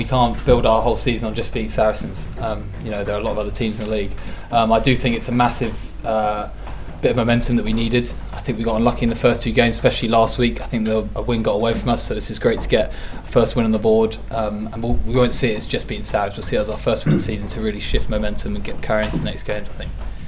After the game Harlequins scrum-half Will Porter spoke of how Quins need to push on from today’s win: